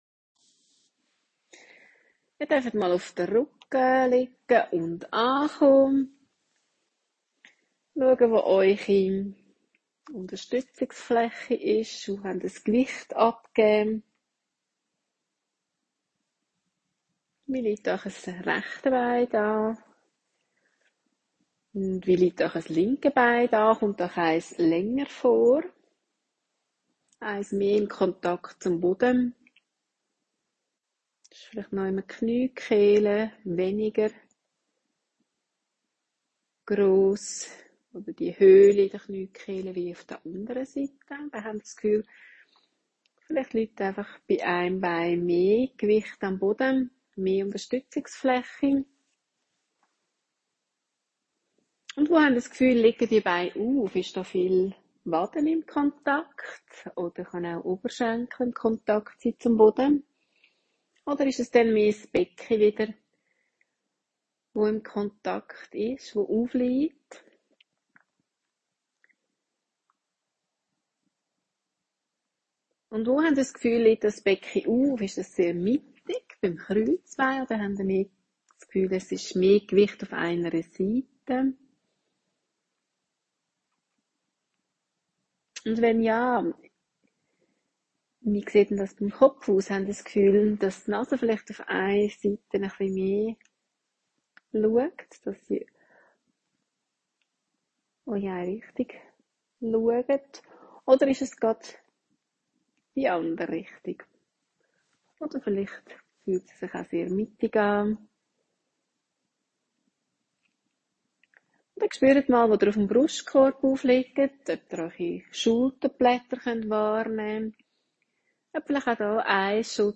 Gruppenunterricht in sensomotorischem Lernen nach der Feldenkrais Methode